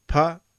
Pa - short vowel sound | 486_14,400